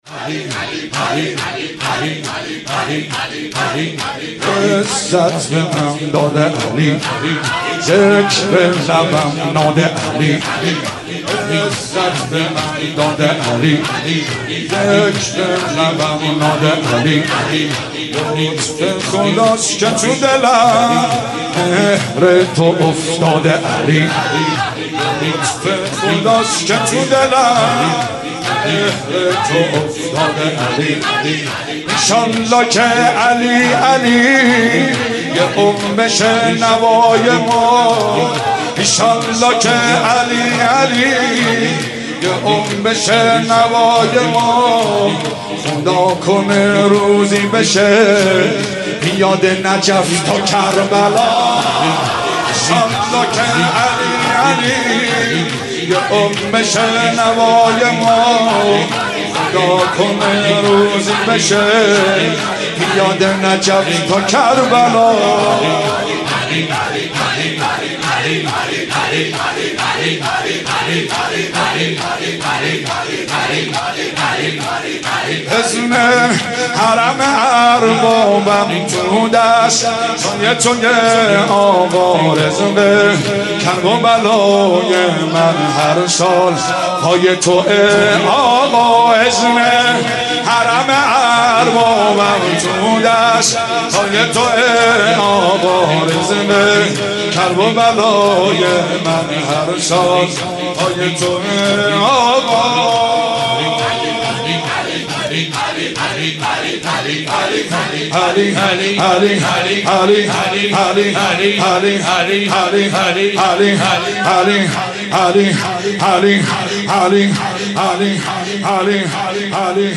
مناسبت : شب بیستم رمضان
مداح : مهدی سلحشور قالب : شور